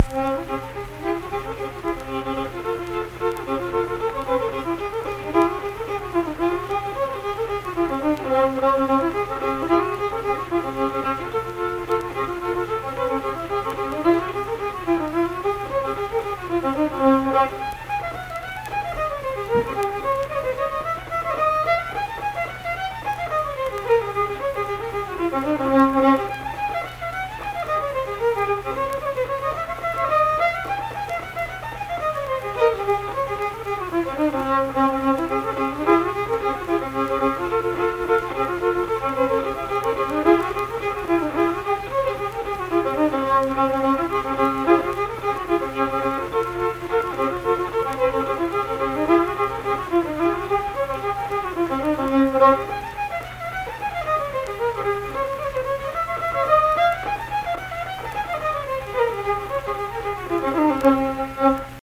Unaccompanied fiddle music
Instrumental Music
Fiddle